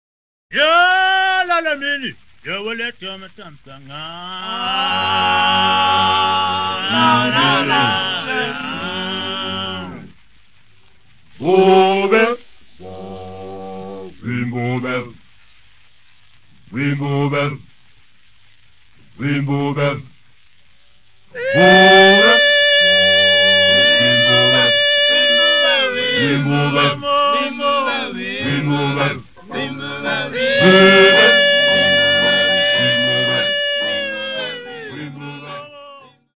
장르이시카타미야